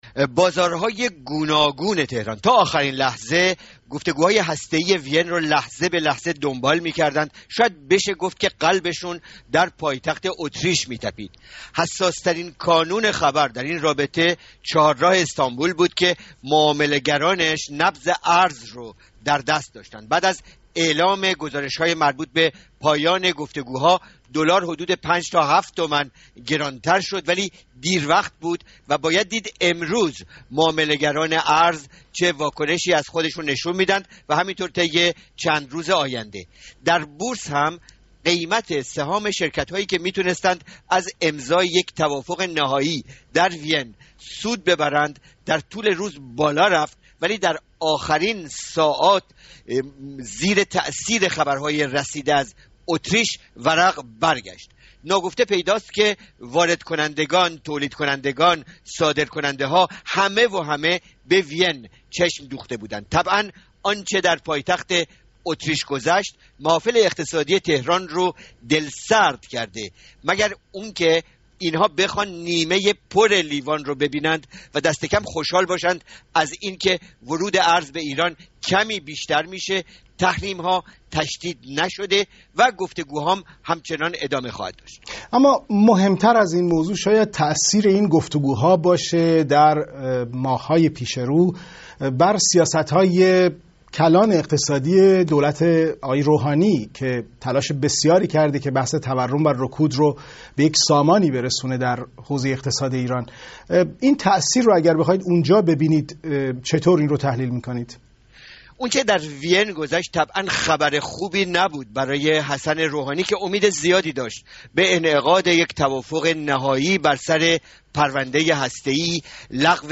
تاثیر مذاکرات هسته‌ای بر بازار ایران؛ گفت‌وگو